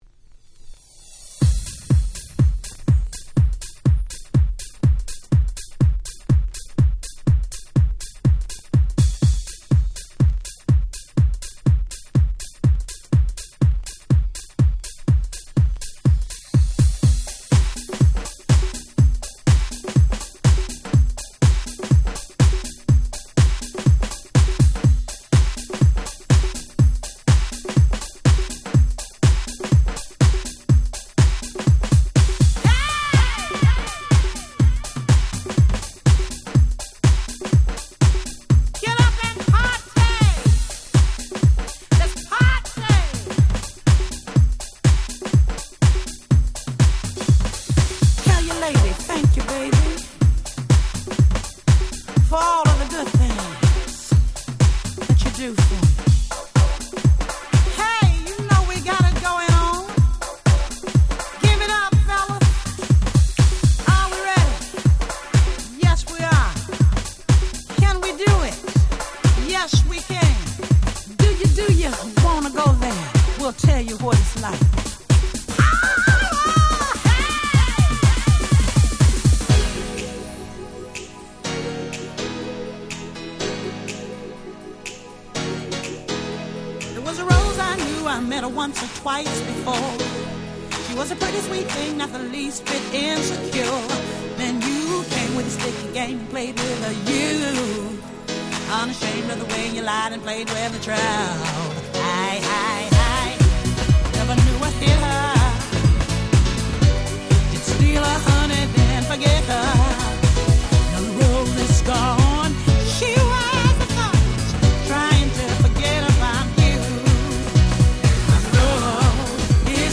98' Smash Hit R&B / Soul !!